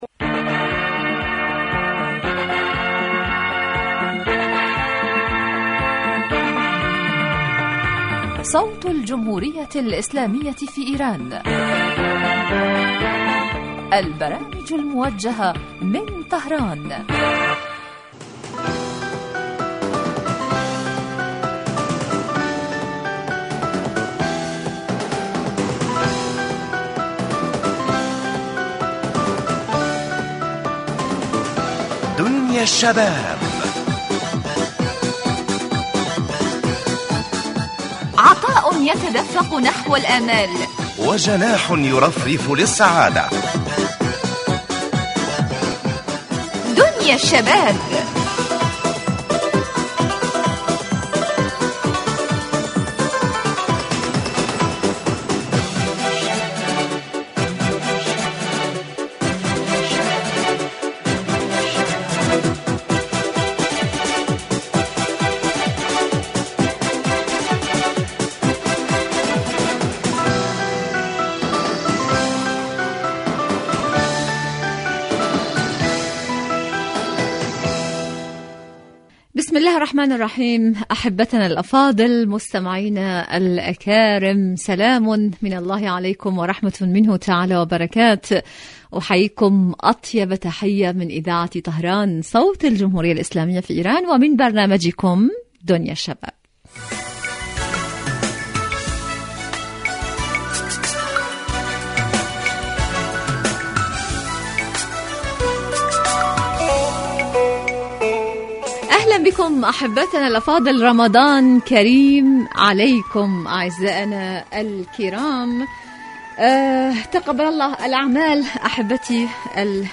برنامج اجتماعي غني بما يستهوي الشباب من البلدان العربية من مواضيع مجدية و منوعة و خاصة ما يتعلق بقضاياهم الاجتماعية وهواجسهم بالتحليل والدراسة مباشرة علي الهواء.